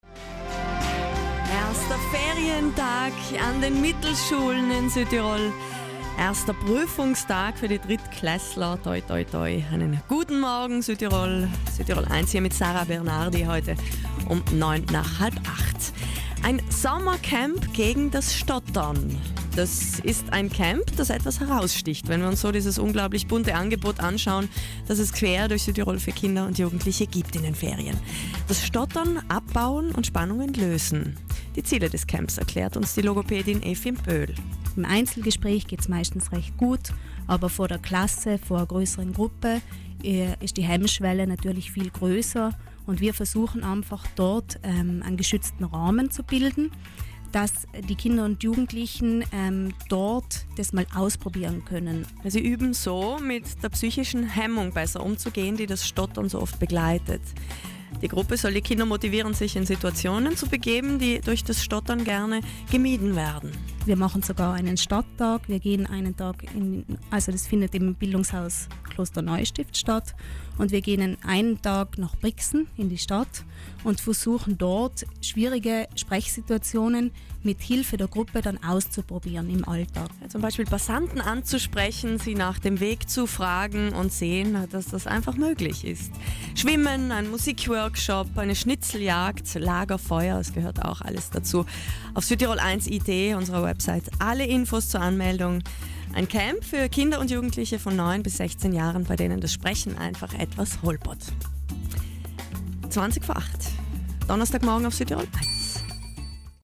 Rai Südtirol Radio und Südtirol1 brachten im Juni  eine Sendung über das 3. Südtiroler Stottercamp 2017.